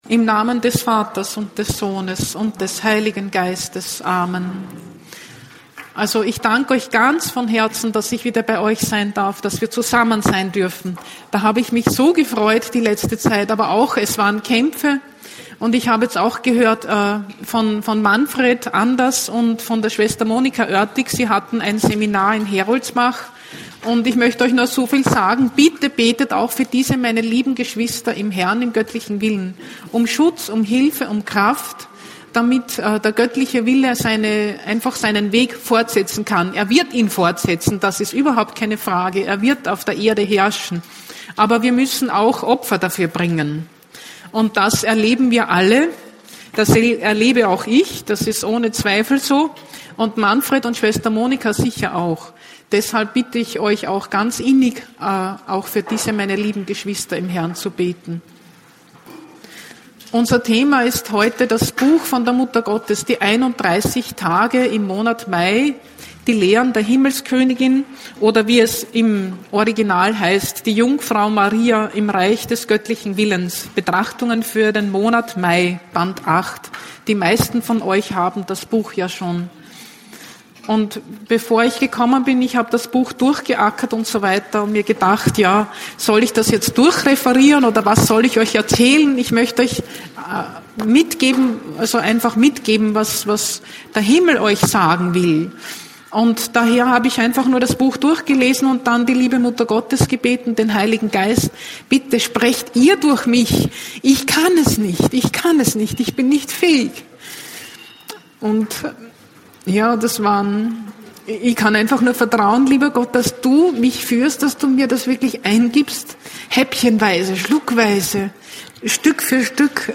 Einkehrtag 5. Oktober 2019 in Bamberg